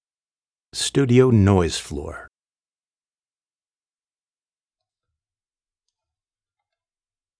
Male
My voice is warm, textured, and engaging, conveying a wide range of emotions.
Studio Quality Sample
Unprocessed Studio Audio Sample
1007Studio_Noise_Floor.mp3